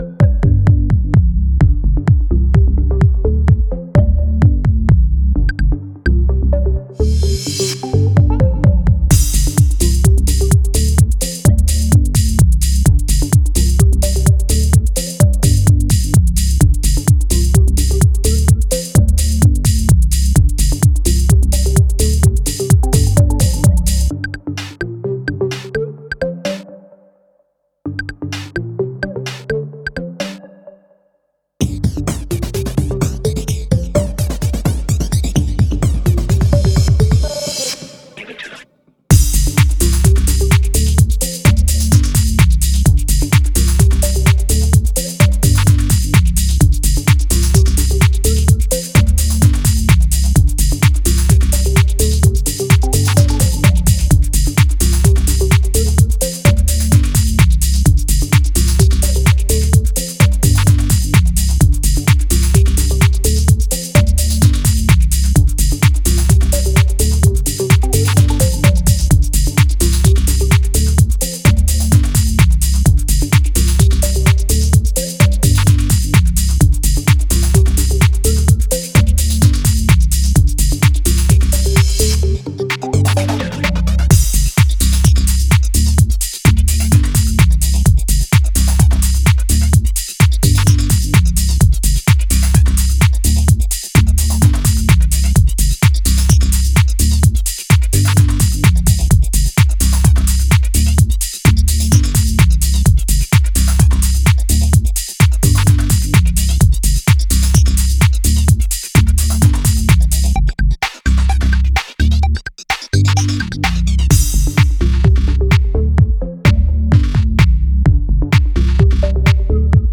emotionally charged house narratives.